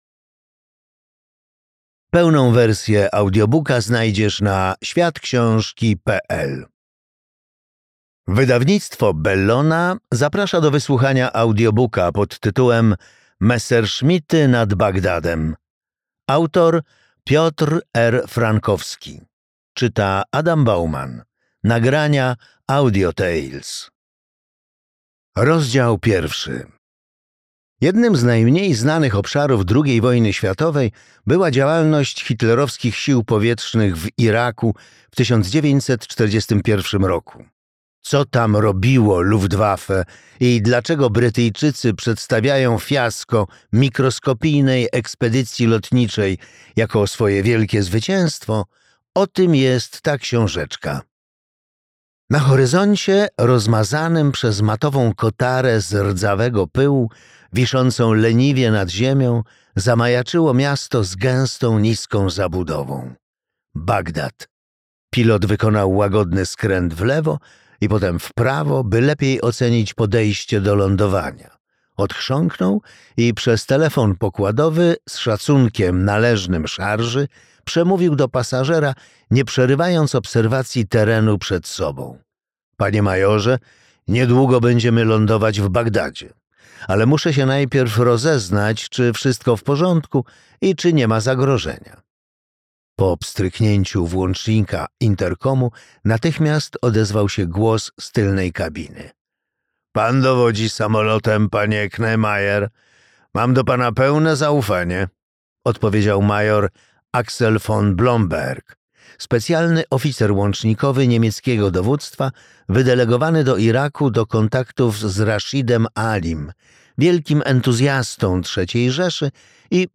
Messerschmitty nad Bagdadem - Piotr Frankowski, Adam Bauman - audiobook